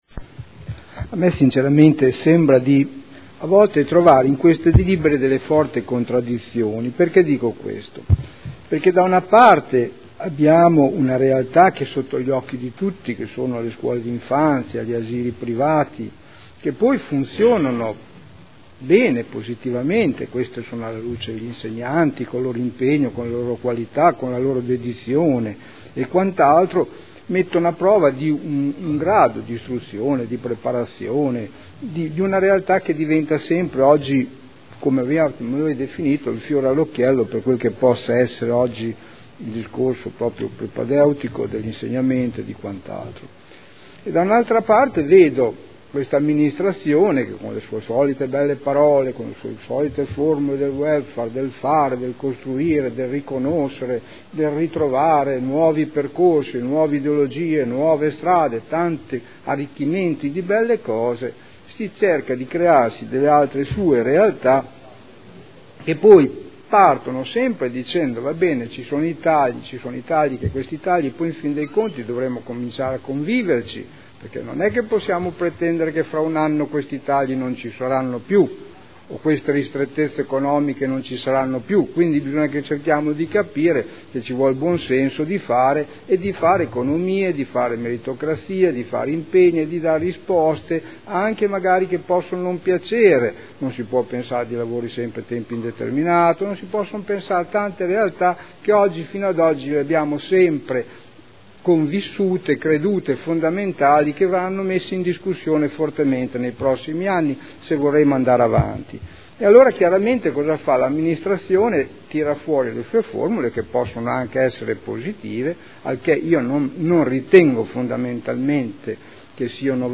Seduta del 03/05/2012. Dibattito su proposta di deliberazione, emendamenti e Ordine del Giorno sulle scuole d'infanzia comunali